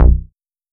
8 BALL BAS-L.wav